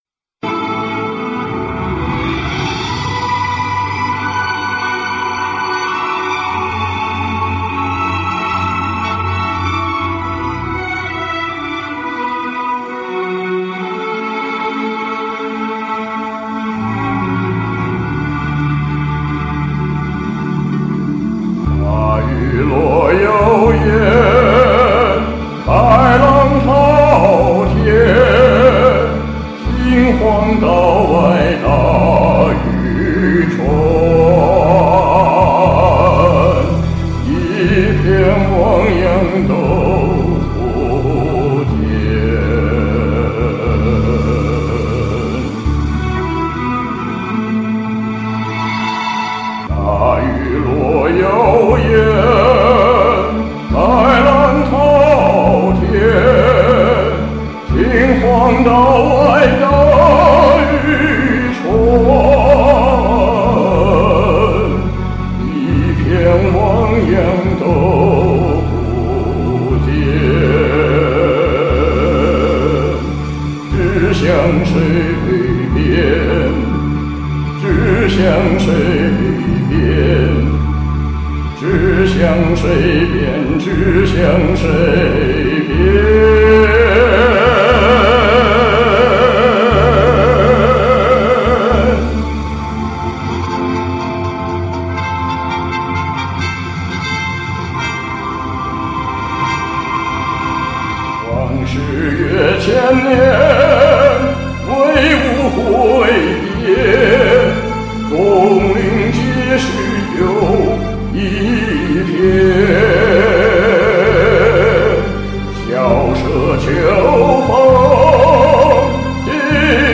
匆匆学唱，请勿见笑。